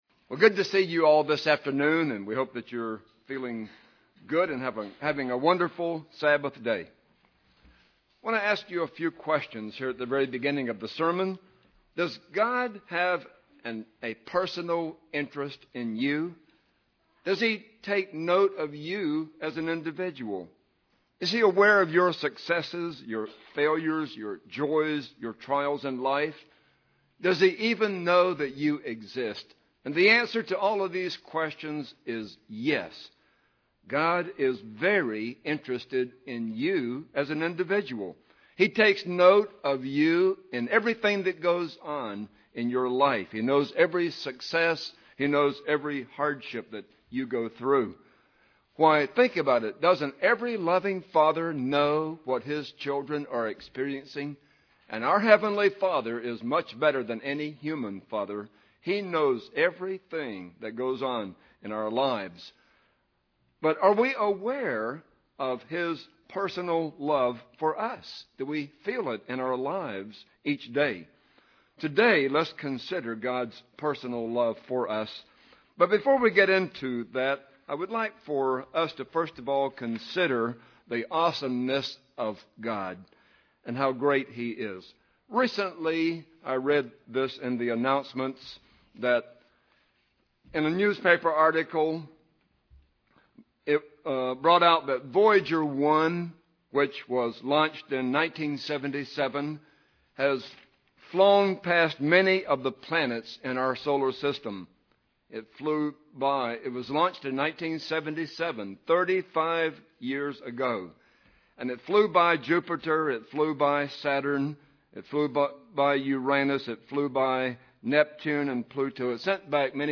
Listen to this sermon to ponder the awesomeness of our Father. Now consider that this awesome God has a personal interest in your life and wants to have a relationship with you!